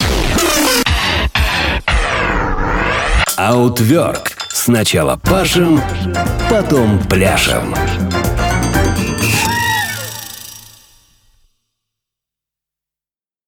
джинглы